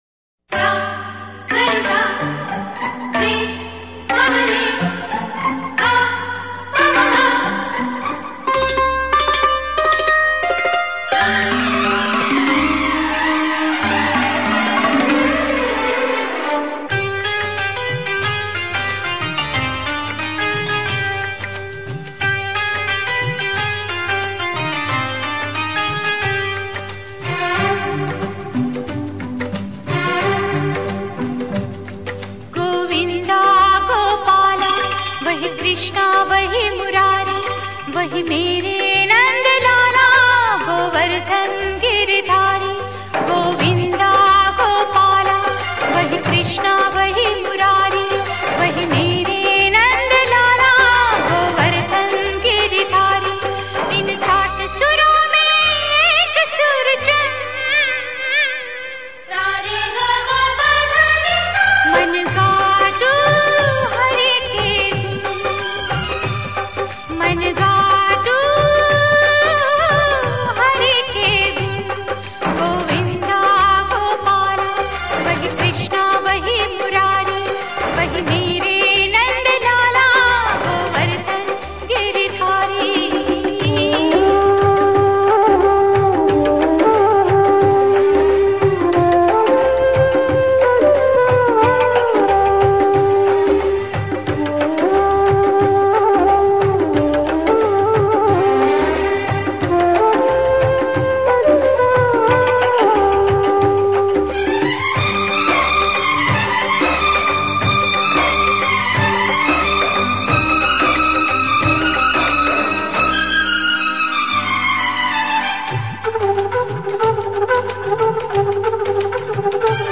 Shree Krishna Bhajans